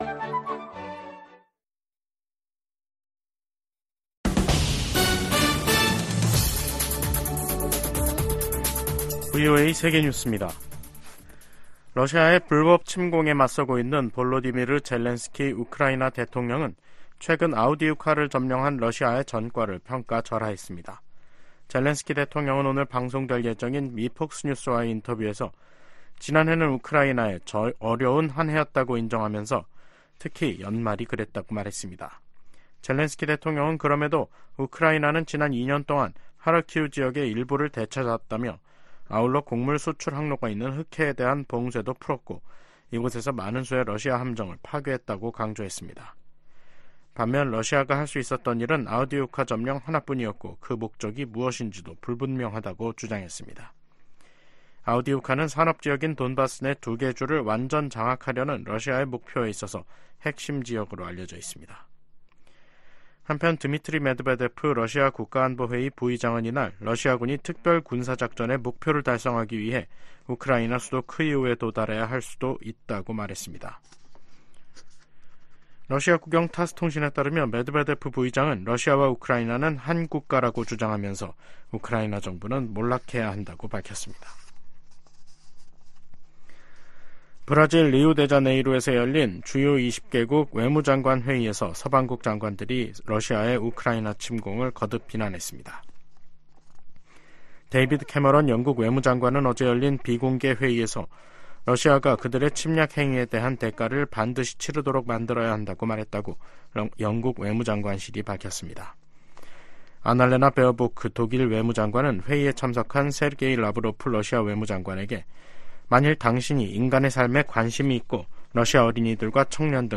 VOA 한국어 간판 뉴스 프로그램 '뉴스 투데이', 2024년 2월 22일 2부 방송입니다. 미 국무부가 북한 대량살상무기·탄도미사일에 사용될 수 있는 민감한 품목과 기술 획득을 막는데 모든 노력을 기울일 것이라고 밝혔습니다. 미 하원에서 우크라이나 전쟁 발발 2주년을 맞아 러시아·북한·중국·이란 규탄 결의안이 발의됐습니다. 한국 정부는 북한과 일본 간 정상회담 관련 접촉 움직임에 관해, 한반도 평화 유지 차원에서 긍정적일 수 있다는 입장을 밝혔습니다.